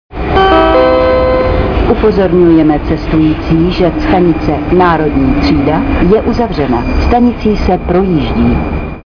- Vlakové hlášení o uzavření stanice si